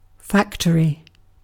Ääntäminen
UK : IPA : /ˈfaktəɹi/